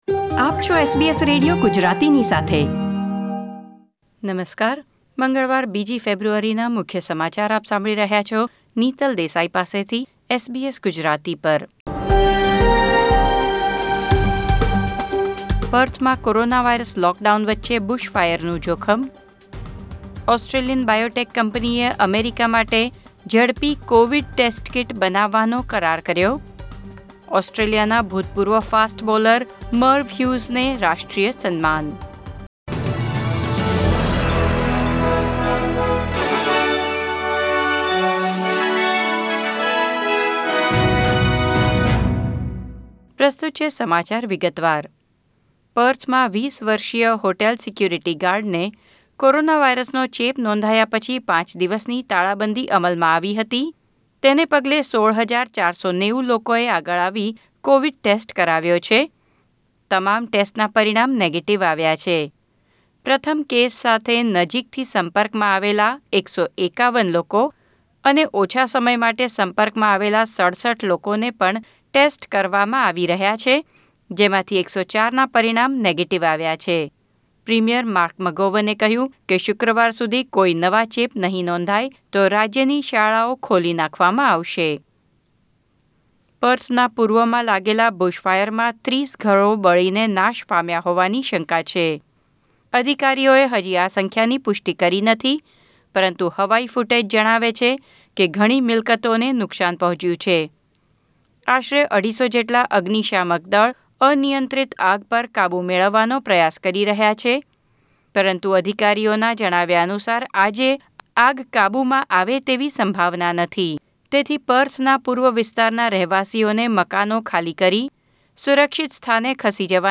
SBS Gujarati News Bulletin 2 February 2021